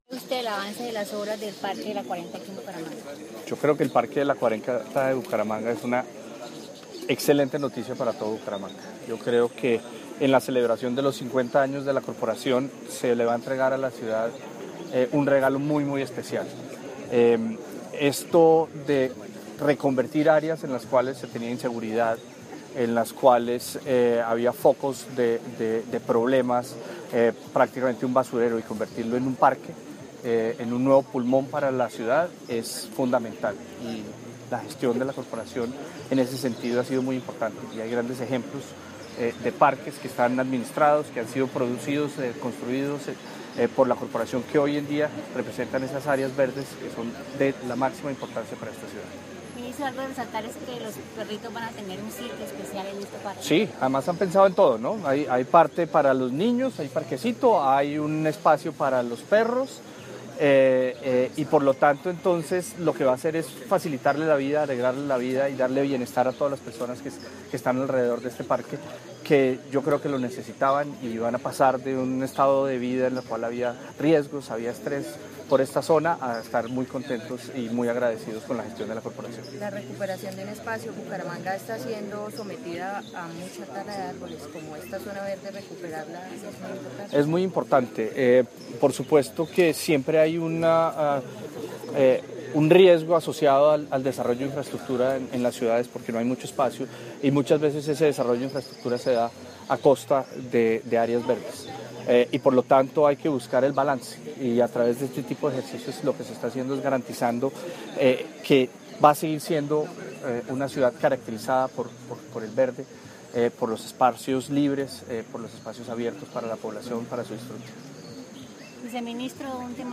Declaraciones del Viceministro de Ambiente y Desarrollo Sostenible, Pablo Viera Samper
21-Declaraciones_Viceministro_Inauguracio_n_De_Parque.mp3